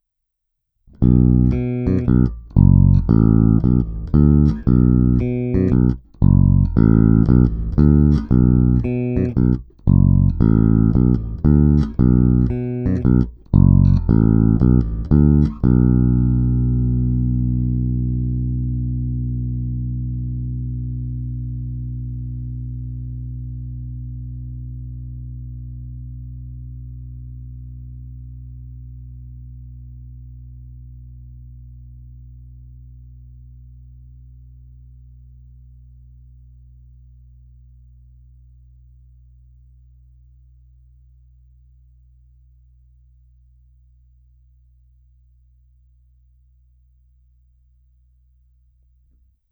Není-li uvedeno jinak, následující nahrávky jsou provedeny rovnou do zvukové karty, jen normalizovány, jinak ponechány bez úprav.
Hráno vždy nad aktivním snímačem, v případě obou pak mezi nimi.
Oba snímače